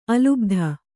♪ alubdha